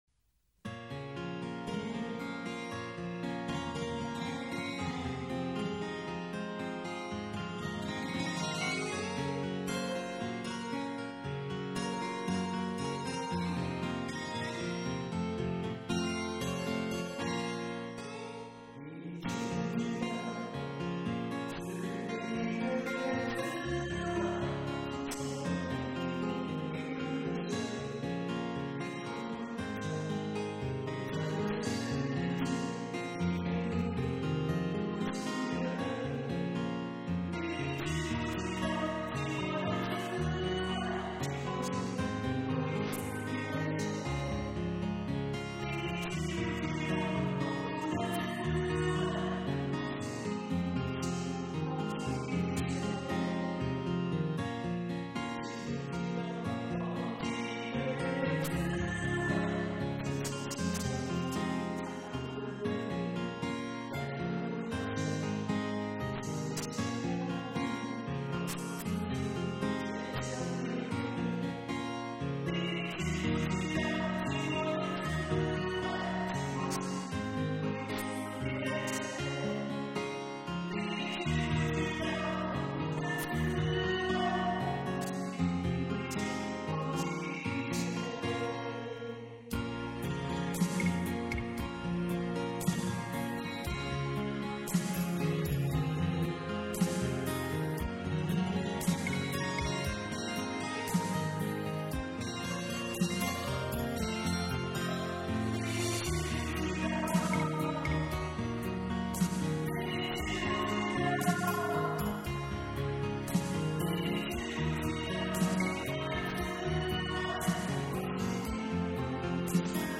校园民谣